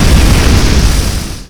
spellDoneFlames.ogg